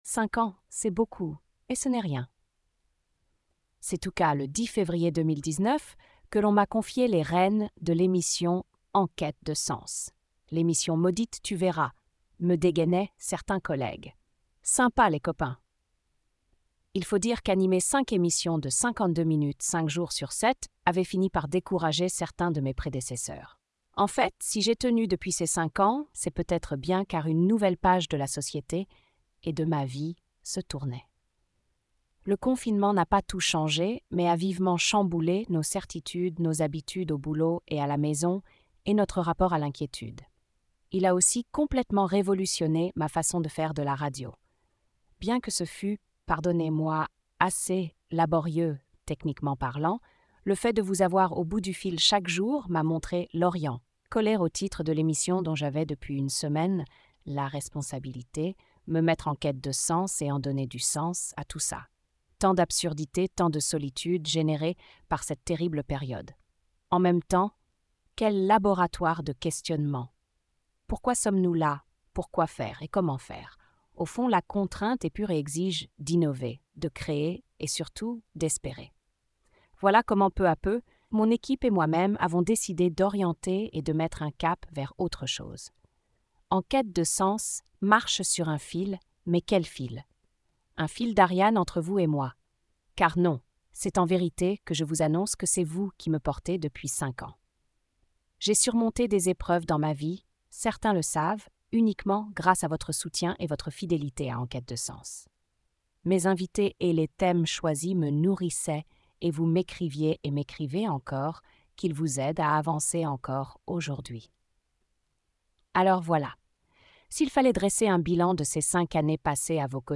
Cette voix est générée par une IA (TTS OpenAI).